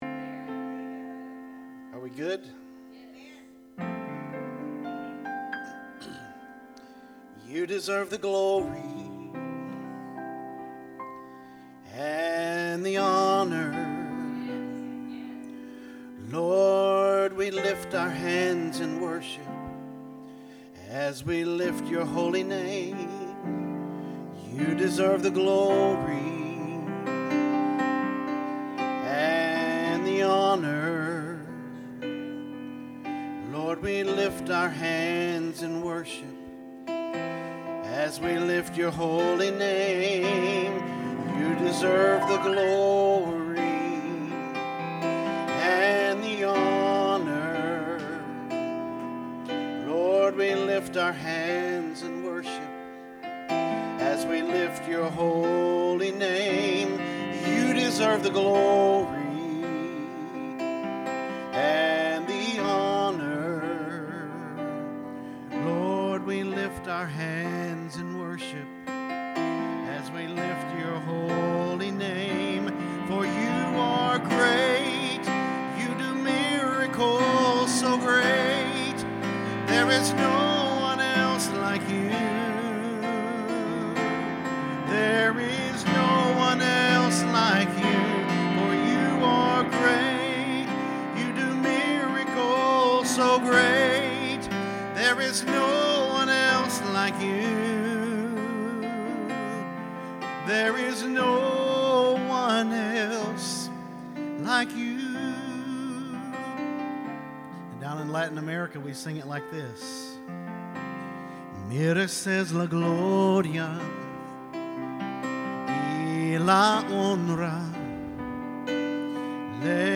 Sermons | New Life Ministries
Guest Speaker